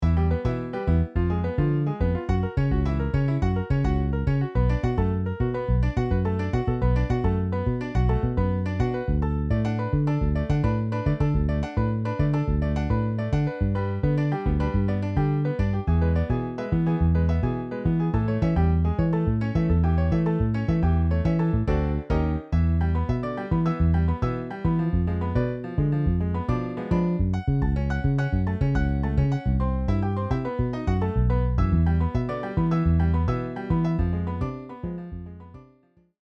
Saxo Alto, 2X Trompetas, 2X Trombones, Piano, Bajo